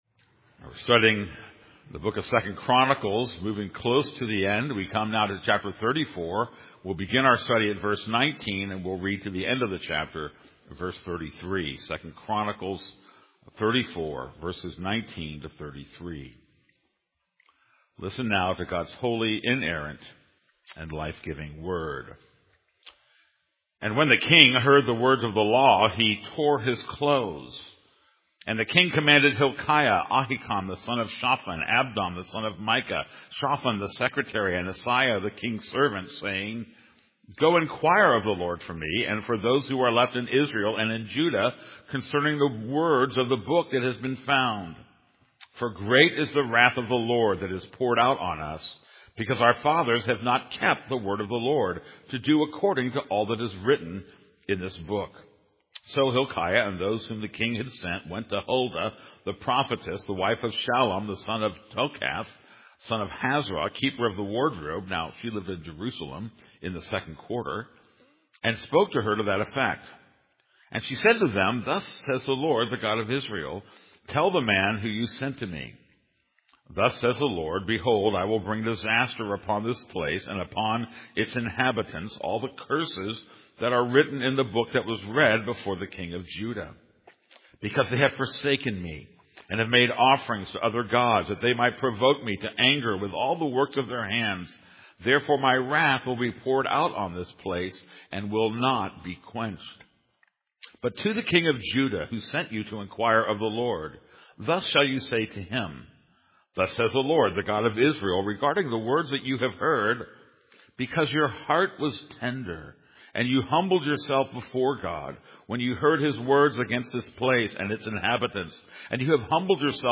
This is a sermon on 2 Chronicles 34:19-33.